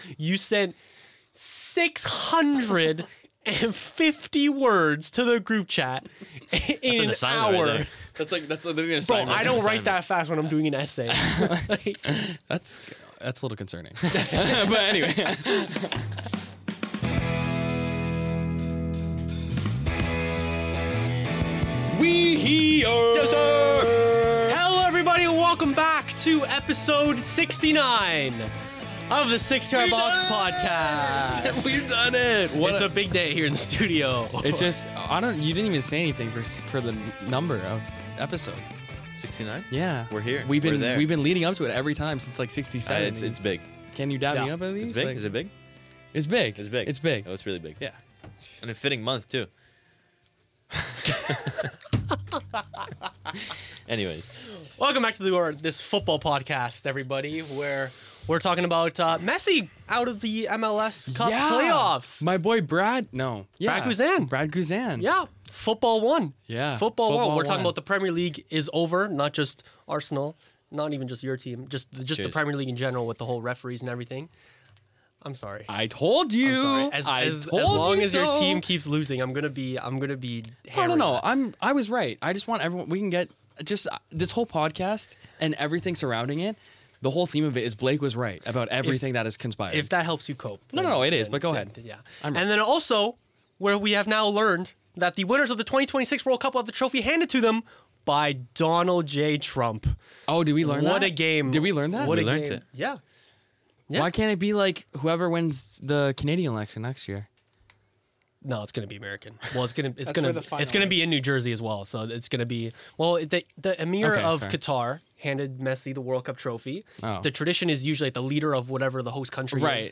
Hosted by a group of four Canadian boys with a passion for the beautiful game, this podcast is all about having fun while discussing the latest football news, debating the latest hot topics, and playing trivia games.